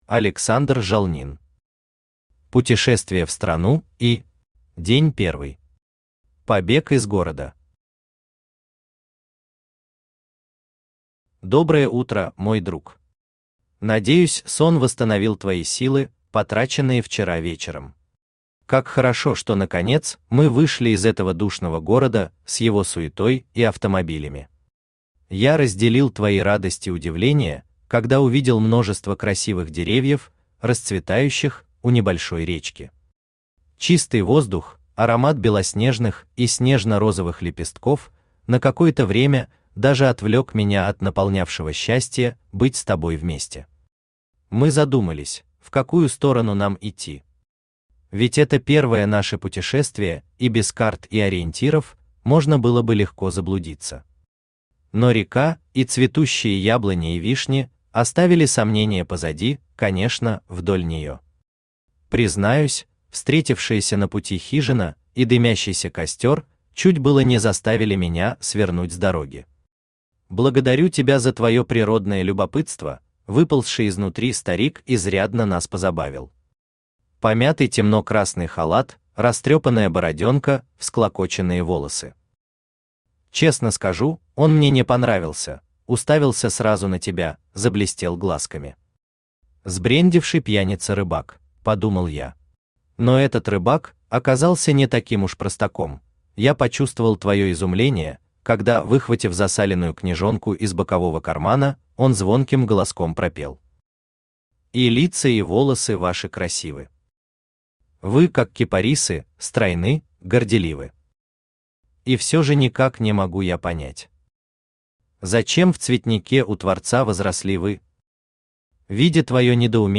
Аудиокнига Путешествие в страну И…
Aудиокнига Путешествие в страну И… Автор Александр Жалнин Читает аудиокнигу Авточтец ЛитРес.